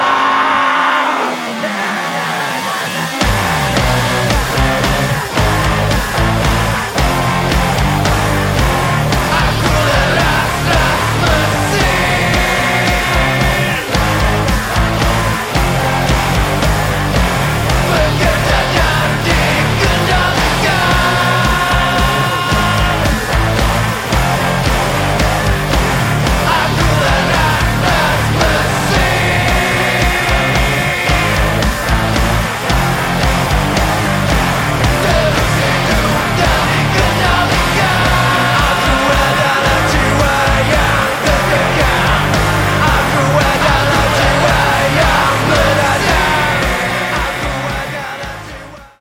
industrial rock